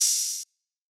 Metro Openhats [Normal].wav